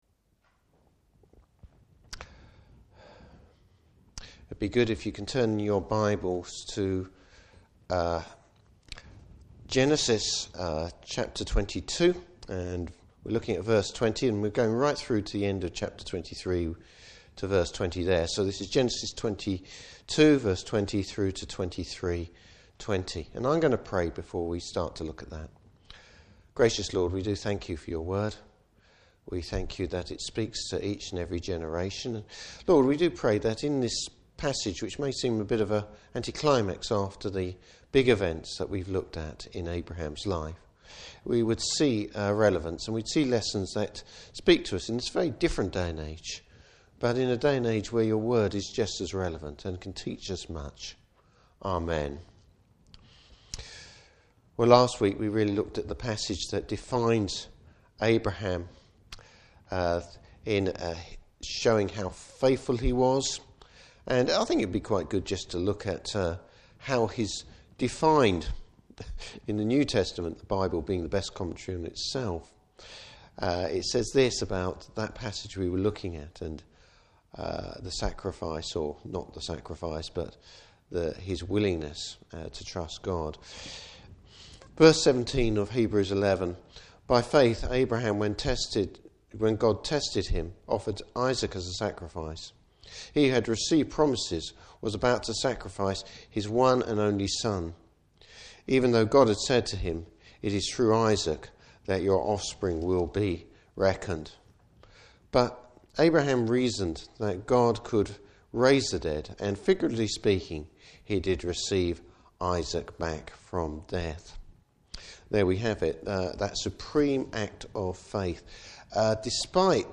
Service Type: Evening Service Abraham confirms his faith in a unspectacular way.